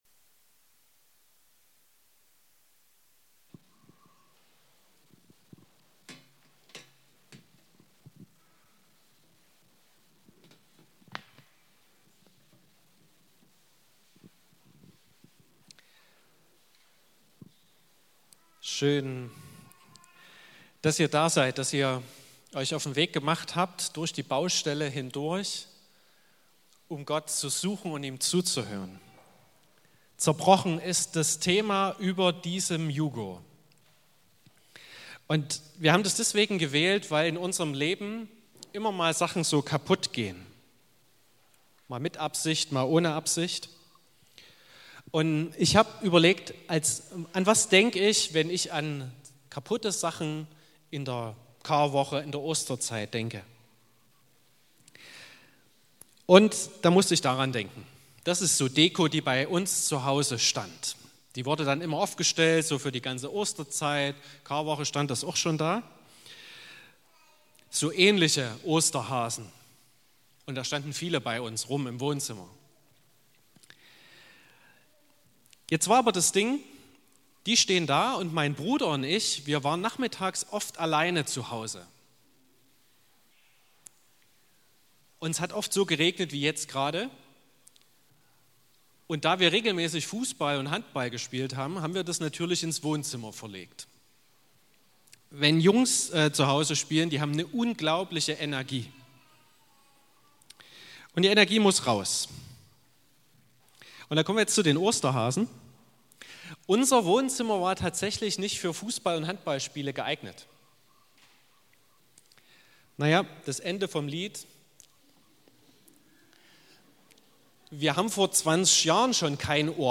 15.04.2022 – Gottesdienst
Predigt (Audio): 2022-04-15_Jugendgottesdienst__Zerbrochen.mp3 (21,8 MB)